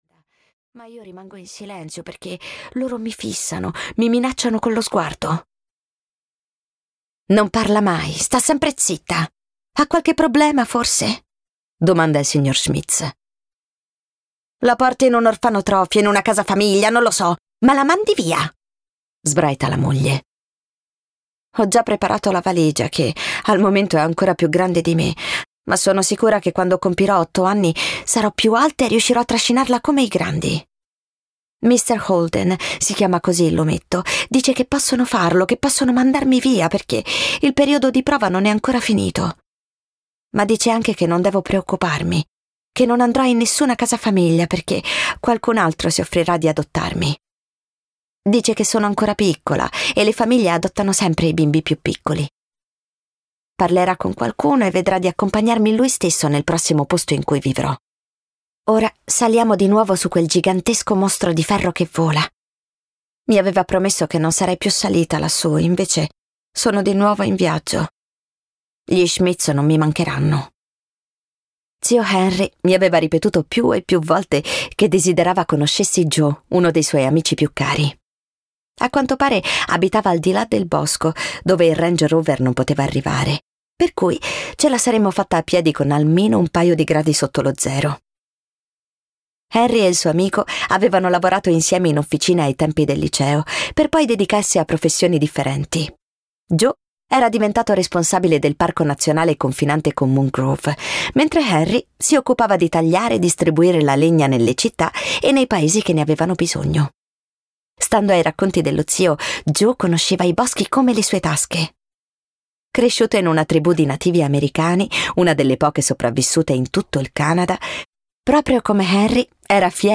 "Liberi come la neve" di Rita Nardi - Audiolibro digitale - AUDIOLIBRI LIQUIDI - Il Libraio